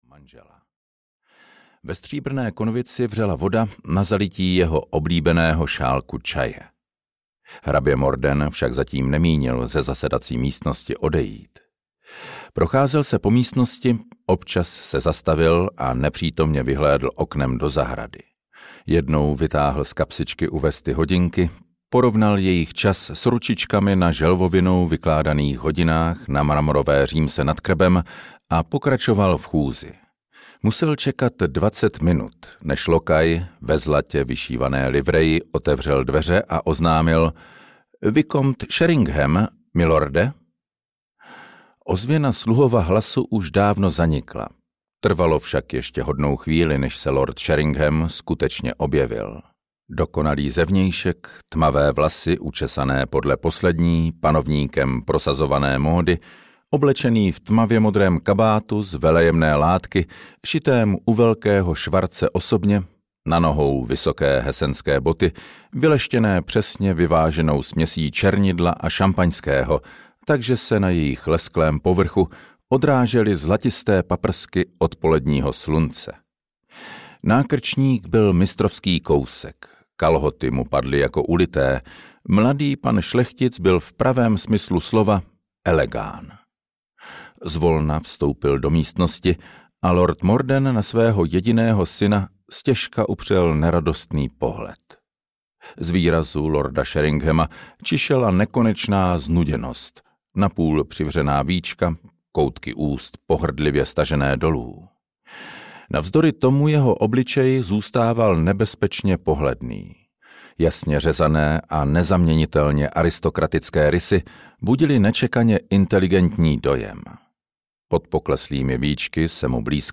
Na křídlech lásky audiokniha
Ukázka z knihy